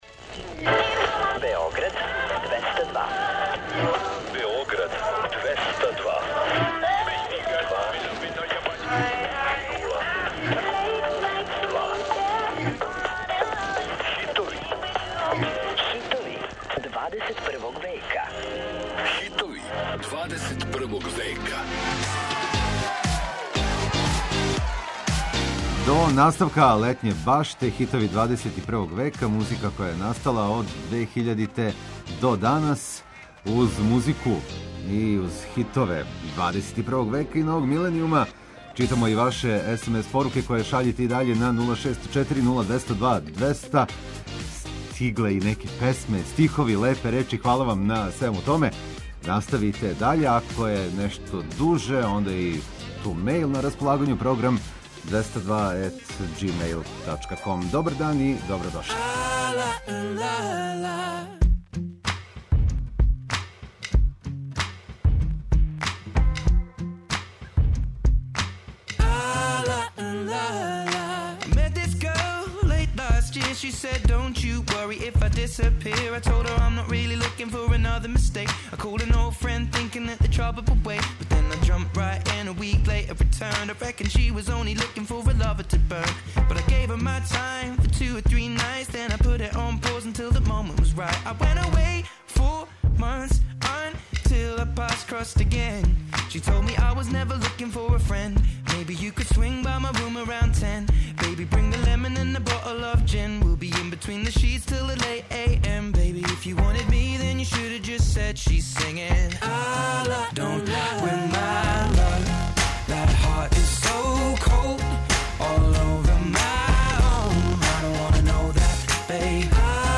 Сваког радног дана, у термину 13-14 сати, подсећамо вас на хитове новог миленијума. Песме које можете да слушате су, од 2000. године до данас, биле хитови недеље Београда 202, или су се налазиле на првим местима подлиста новитета Топ листе 202.